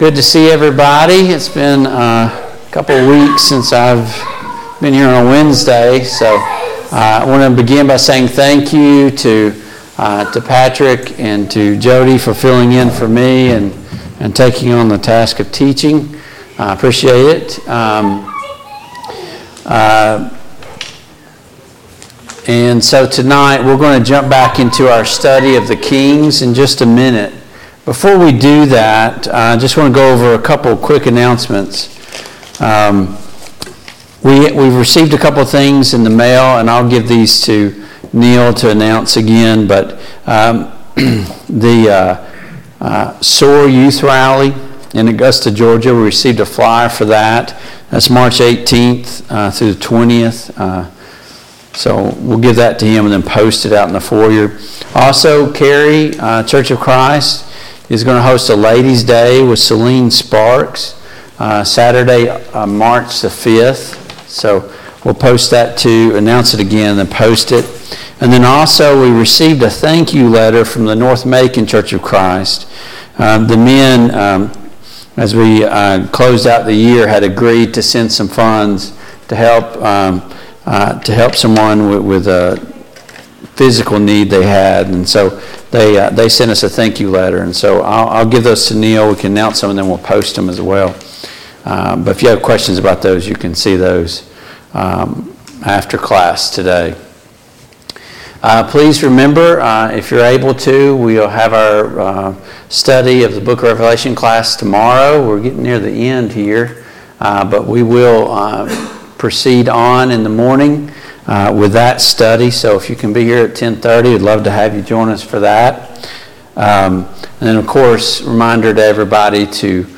Passage: II Samuel 2, II Samuel 3 Service Type: Mid-Week Bible Study